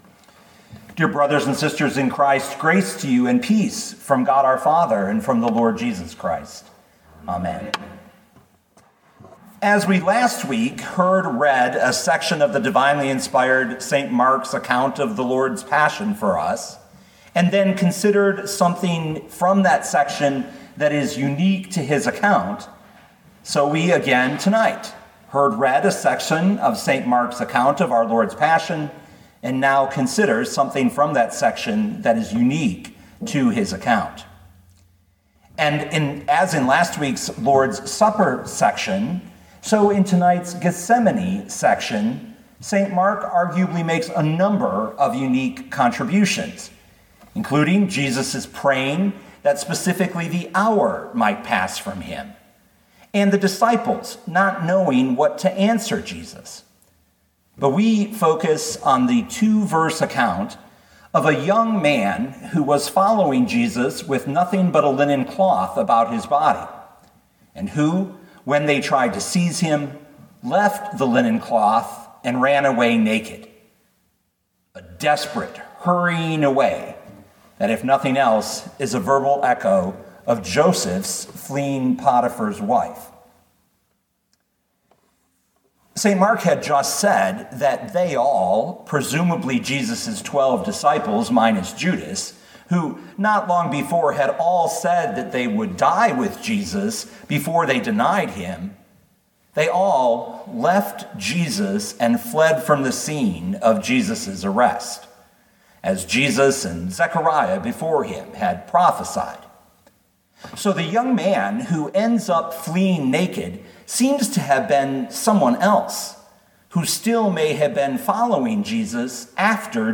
2021 Mark 14:51-52 Listen to the sermon with the player below, or, download the audio.